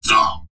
文件 文件历史 文件用途 全域文件用途 Enjo_atk_04_1.ogg （Ogg Vorbis声音文件，长度0.5秒，147 kbps，文件大小：9 KB） 源地址:地下城与勇士游戏语音 文件历史 点击某个日期/时间查看对应时刻的文件。